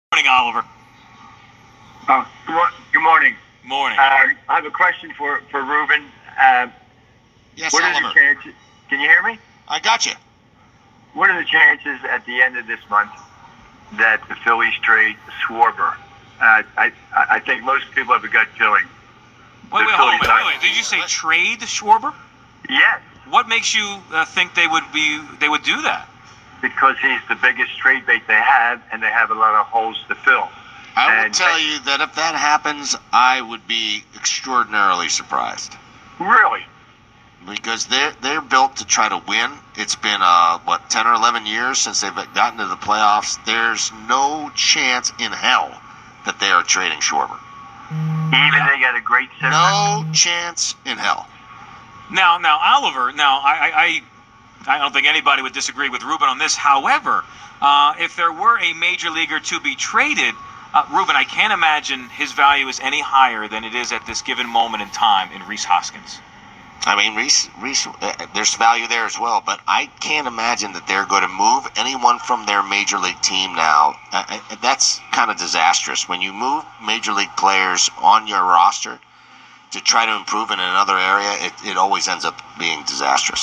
94 WIP Caller Asks if Phillies Should Trade Kyle Schwarber